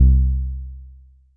BASS1 C2.wav